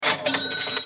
coins.wav